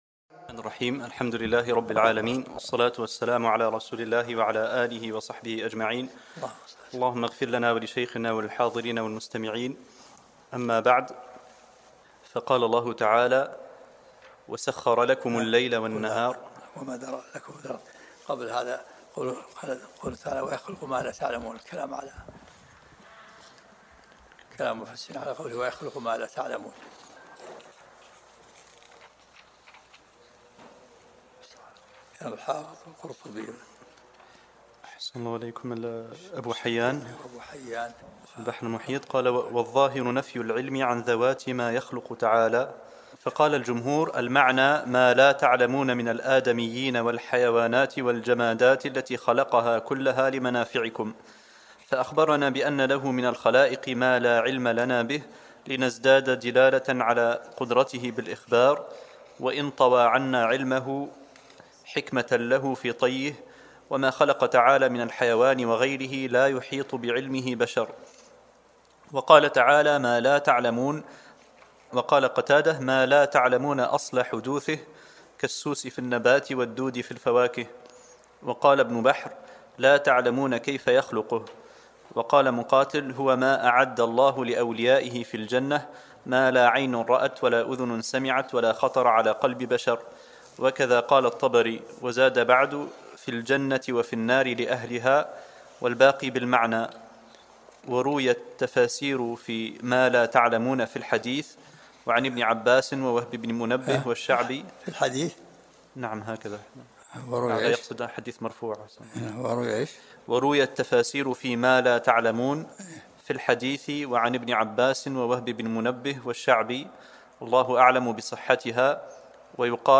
الدرس الثاني من سورة النحل